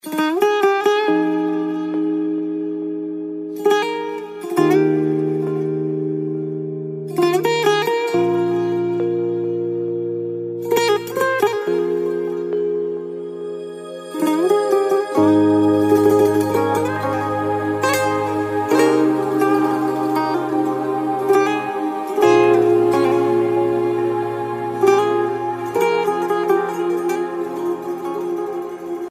آرام و ملایم